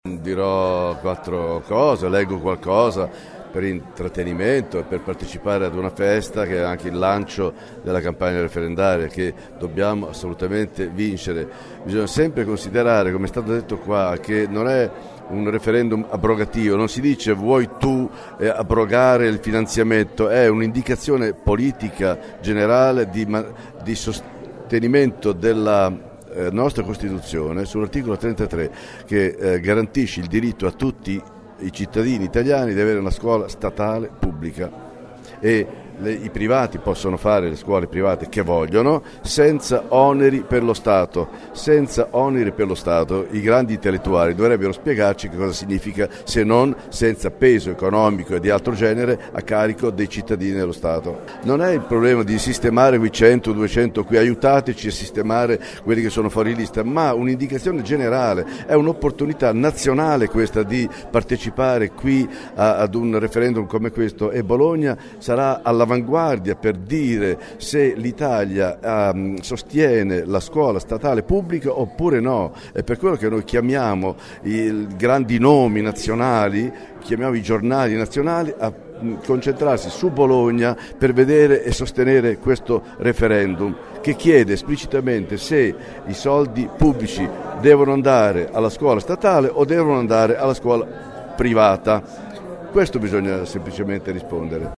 Tra i sostenitori della richiesta di abolizione di finanziamenti comunali alle private c’è anche Ivano Marescotti. L’abbiamo intervistato